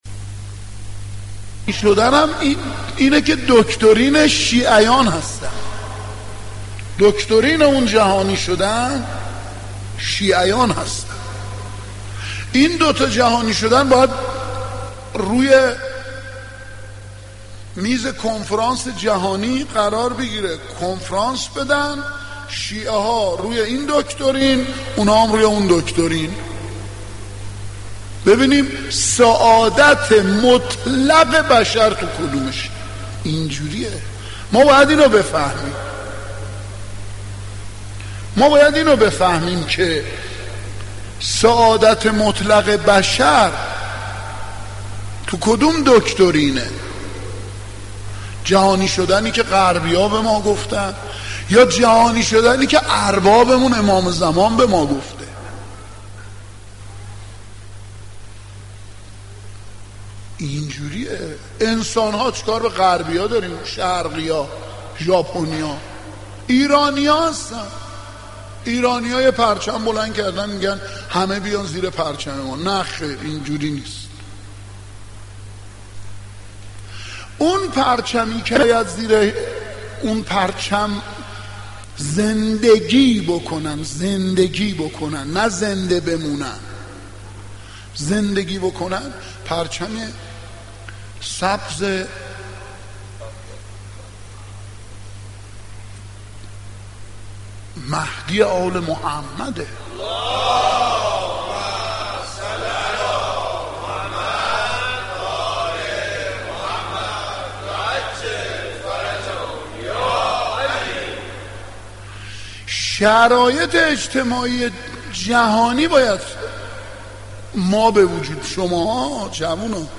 سخنرانی های